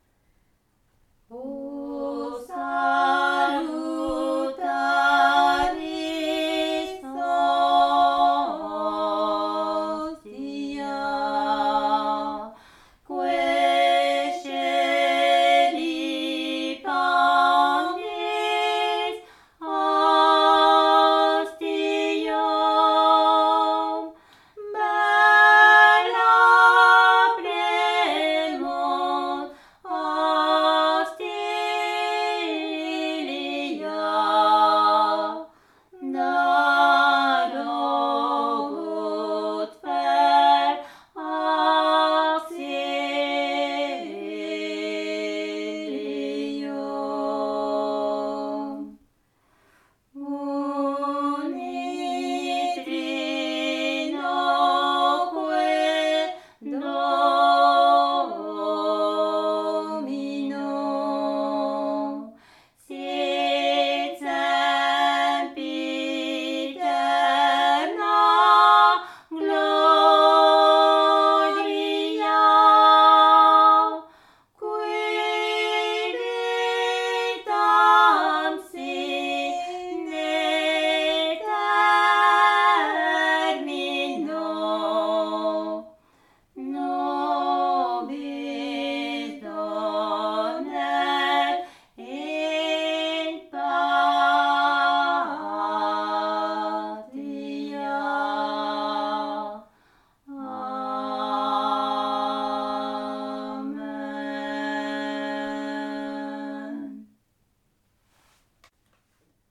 Aire culturelle : Bigorre
Lieu : Ayros-Arbouix
Genre : chant
Effectif : 2
Type de voix : voix de femme
Production du son : chanté
Classification : cantique